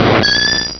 Cri de Yanma dans Pokémon Diamant et Perle.